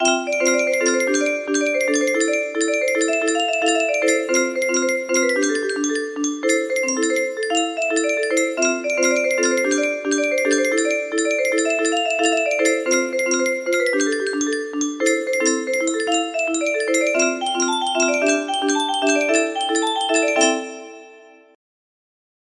【chorus only】